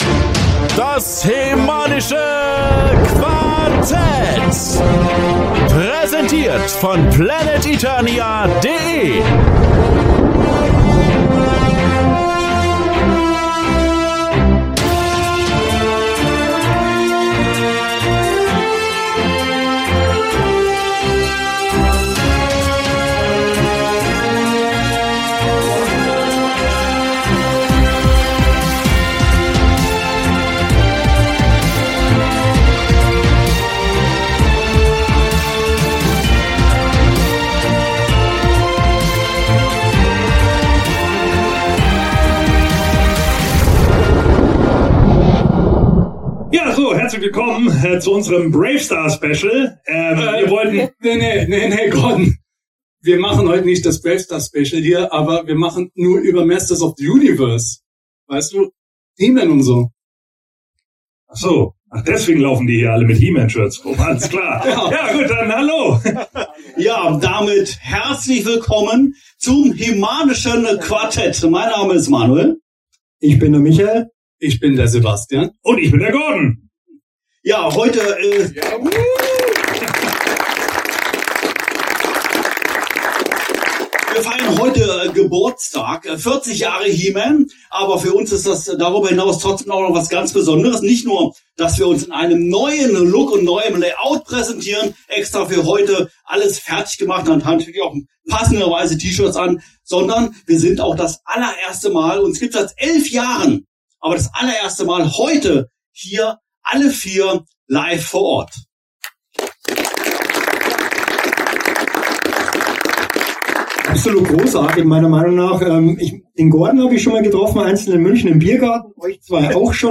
"40 Jahre He-Man" wurde im Oktober 2022 im Luxor Filmpalast in Bensheim gefeiert und unsere vier Nerds waren LIVE ON STAGE und nehmen sich He-Mans Geburtstag zum Anlass, um über ihre besonderen He-Man Erinnerungen der letzten vier Jahrzehnte zu philosophieren.